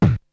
Beatbox 12.wav